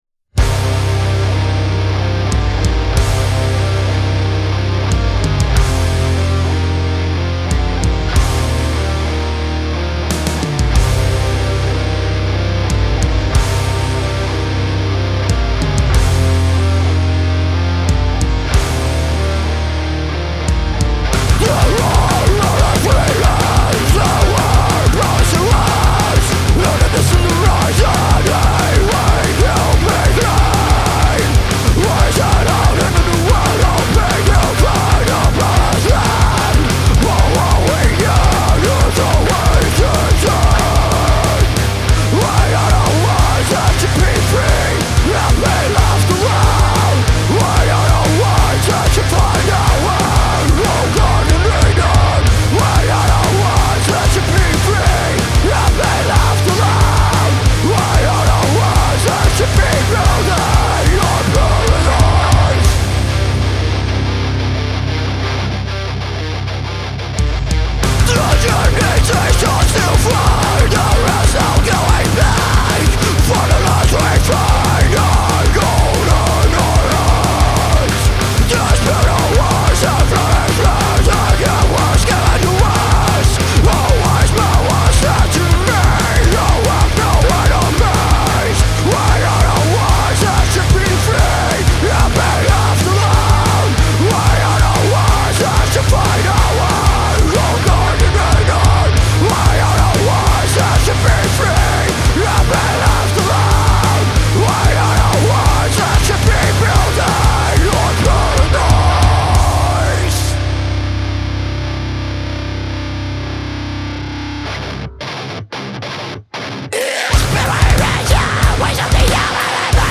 Wir sind eine fast vollständige Band im Bereich Thrash / Death / Metalcore und suchen noch den fehlenden Schreihals am Mikrofon.
– Eigene Songs (keine Cover-Band)